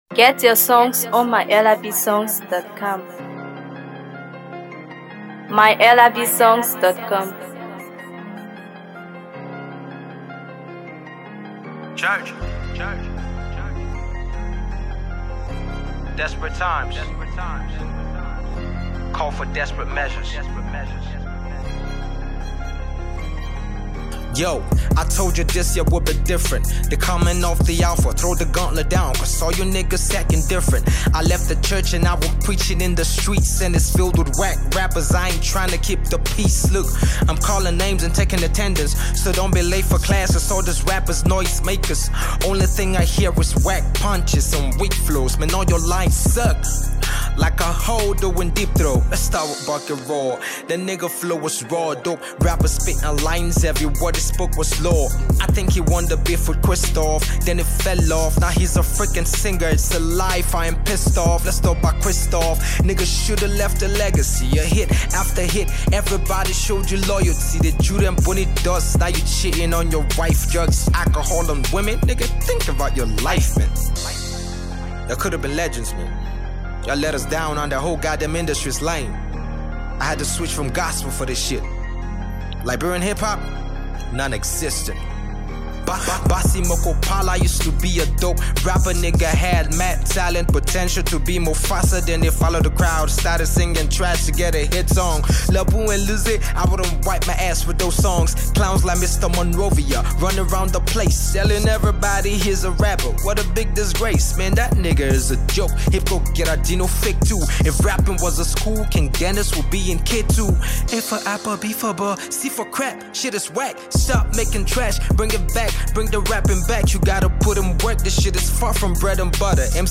Hip HopMusic
With a commanding delivery and razor-sharp lyrics